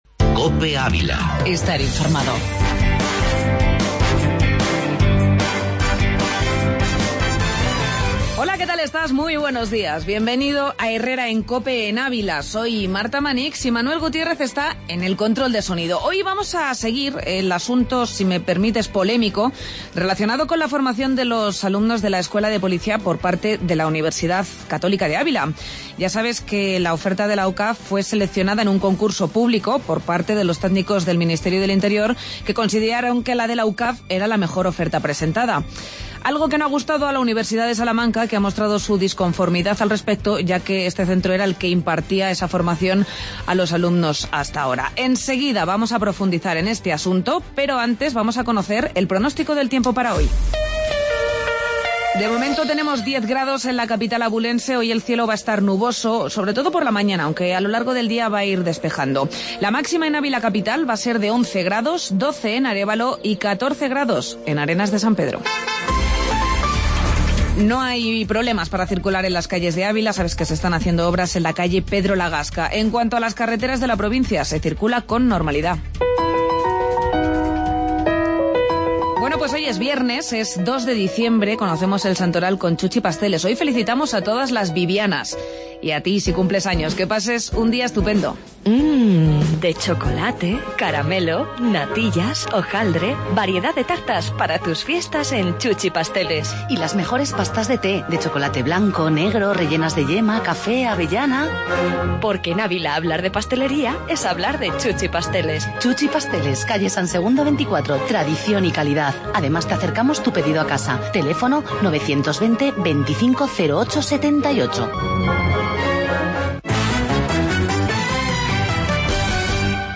AUDIO: Magazine local en Cope Ávila